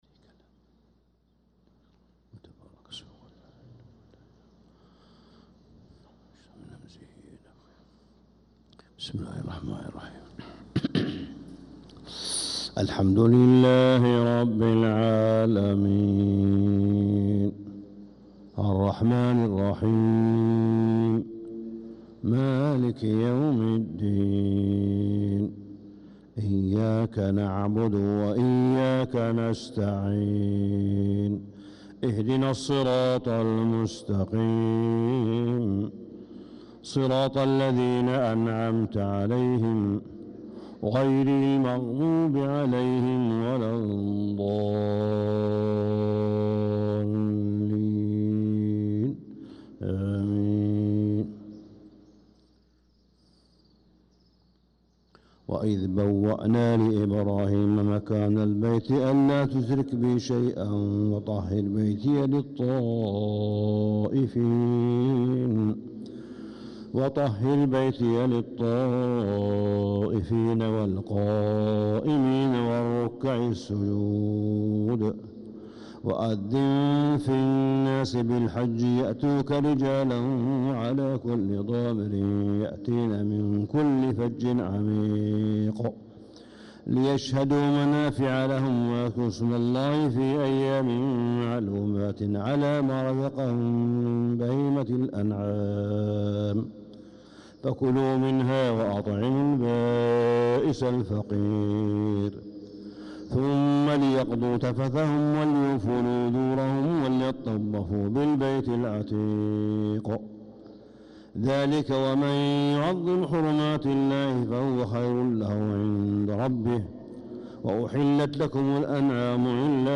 صلاة الفجر للقارئ صالح بن حميد 5 ذو الحجة 1445 هـ
تِلَاوَات الْحَرَمَيْن .